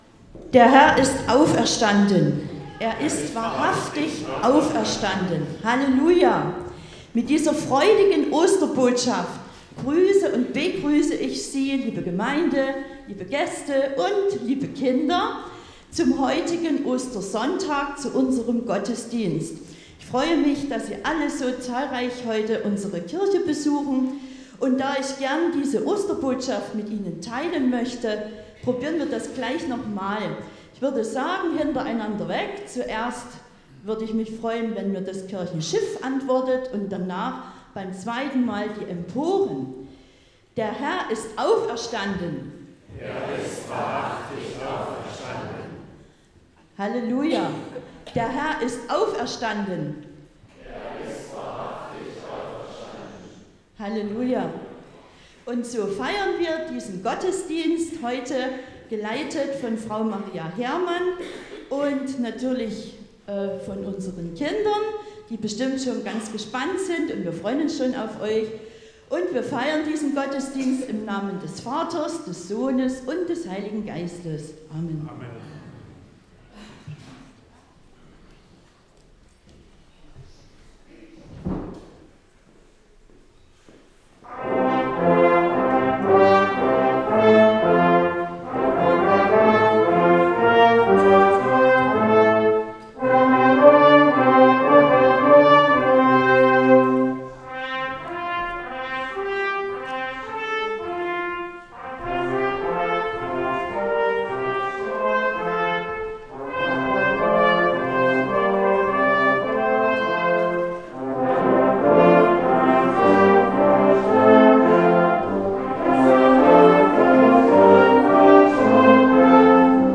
Audiomitschnitt
Gottesdienst_MP3.mp3